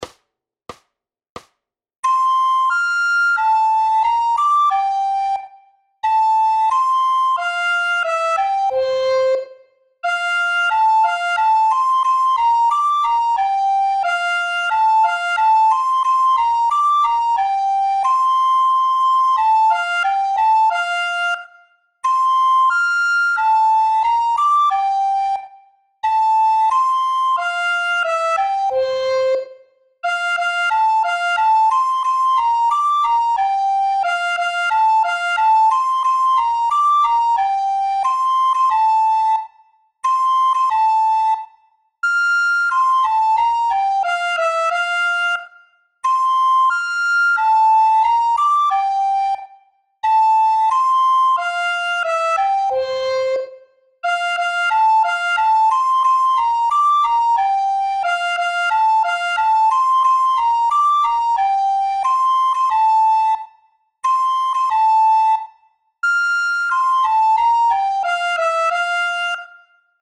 Hudební žánr Vánoční písně, koledy